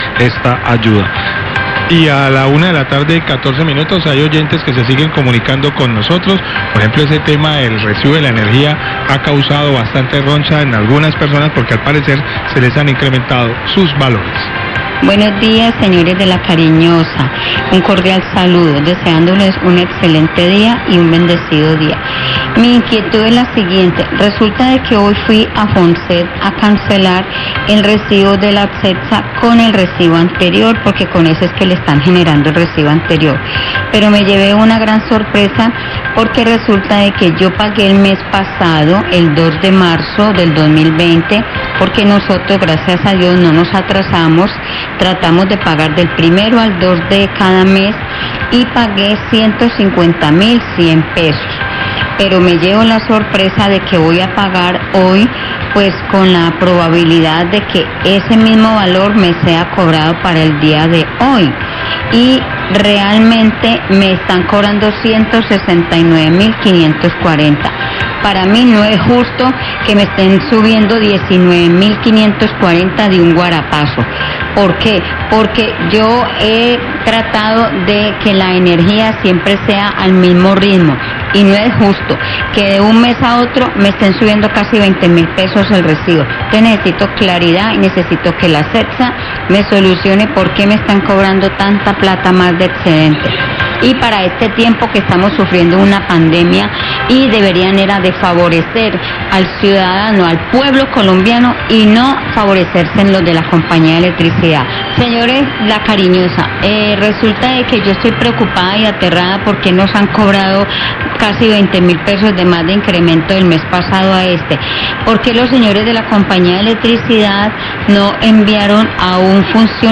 Oyente se queja por el alto costo del servicio de energía, La Cariñosa, 114PM
Radio
Llamada de oyente que se queja por el alto costó de la factura de energía, señala que en vez de cobrarle el promedio como se indicó, se incrementó la factura en casí $20.000.  Pide a la empresa que haga la lectura del medidor porque ella considera que el consumo de energía no subió.